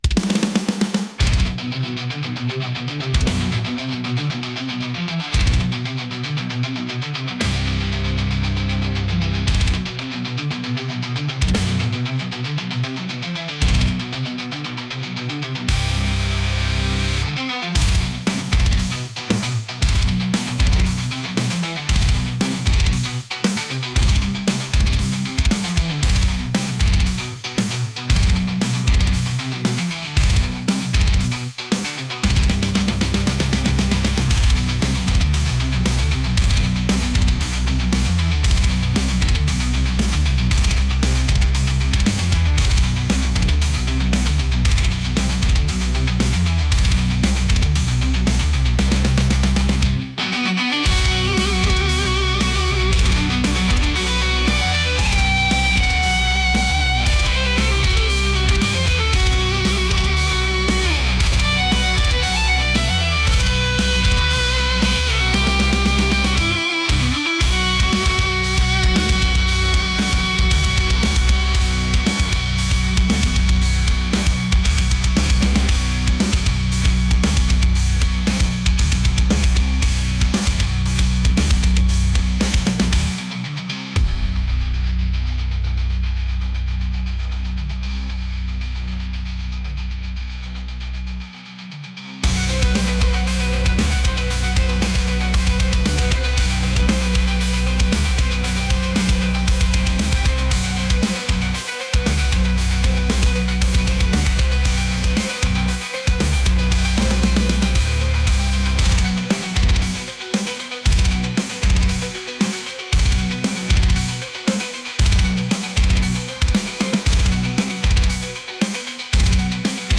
metal | aggressive | heavy